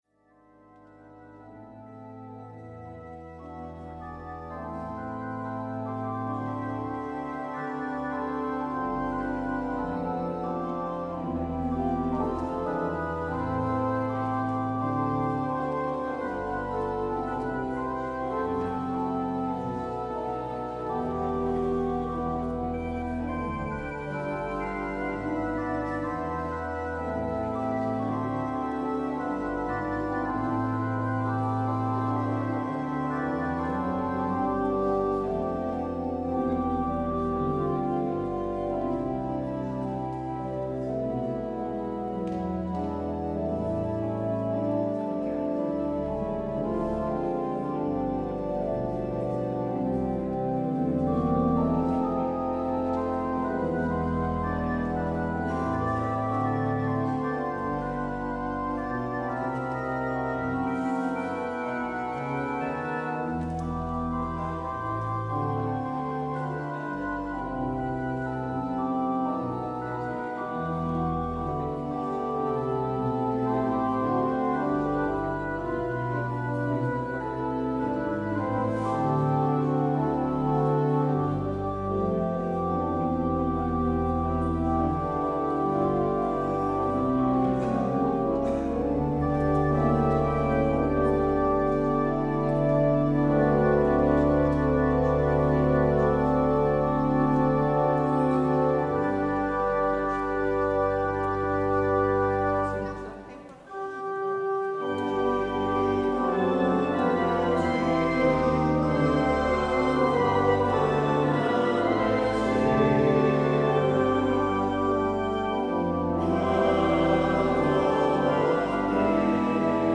Het openingslied is: NLB 314: 1, 2 en 3.
Het slotlied is: NLB 912: 1- 6.